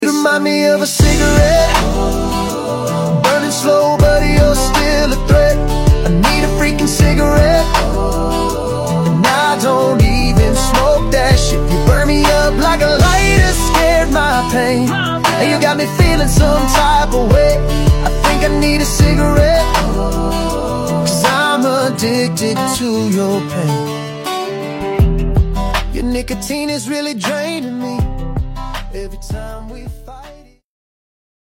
country trap and emotional hip-hop
It’s that genre-blending wave—alt-R&B meets country grit.